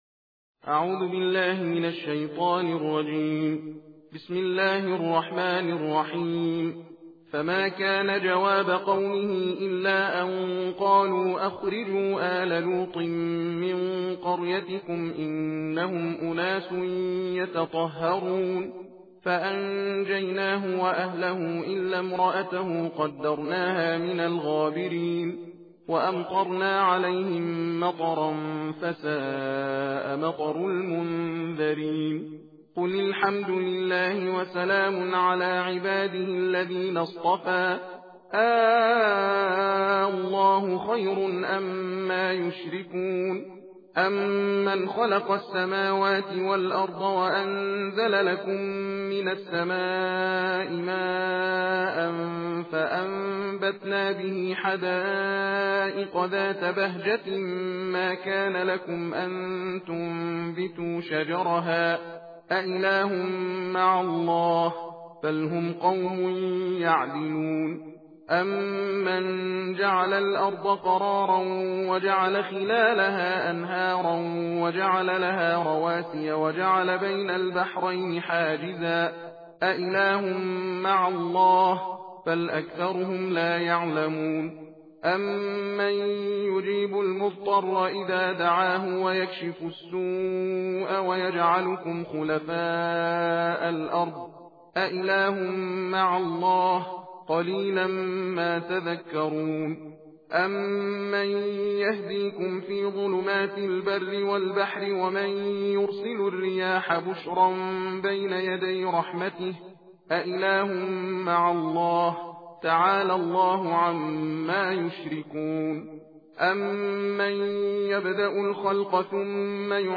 صوت/ تندخوانی جزء بیستم قرآن کریم